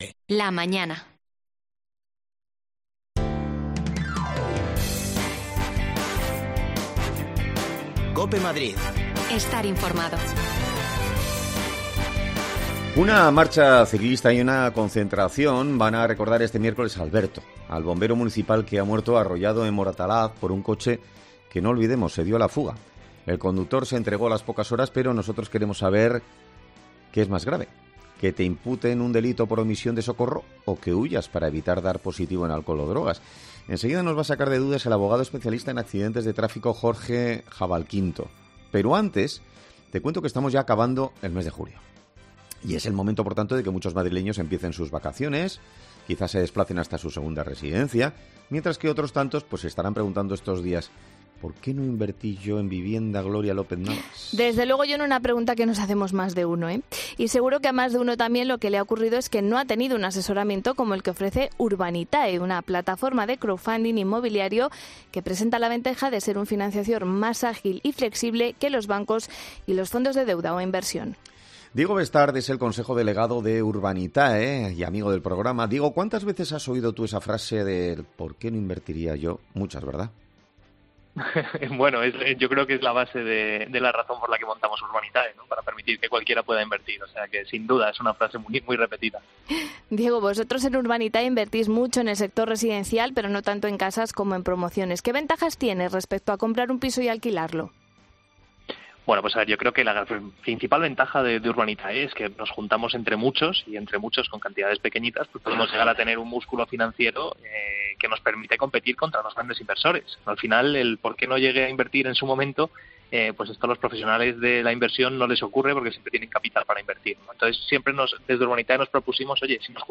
AUDIO: Hablamos con un abogado experto en accidentes de tráfico sobre los delitos de homicidio imprudente al volante y las consecuencias de darse a...
Las desconexiones locales de Madrid son espacios de 10 minutos de duración que se emiten en COPE, de lunes a viernes.